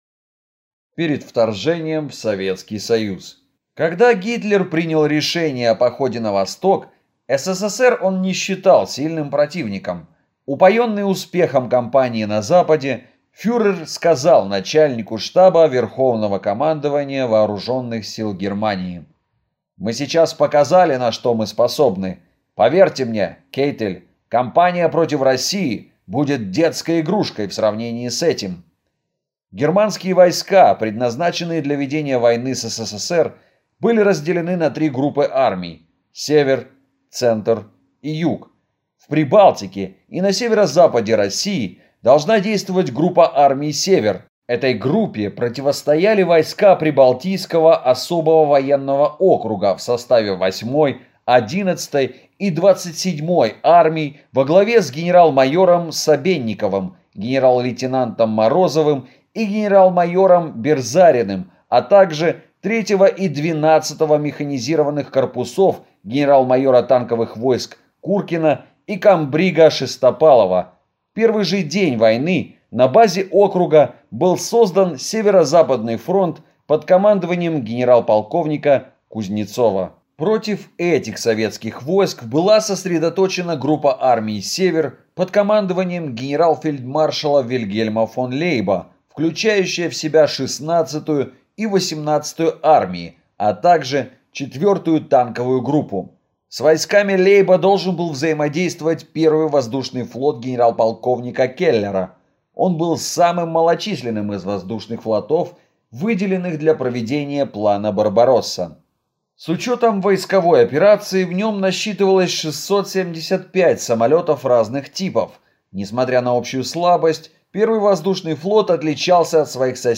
Аудиокнига Разгром дивизии «Мертвая голова». Демянская катастрофа эсэсовцев | Библиотека аудиокниг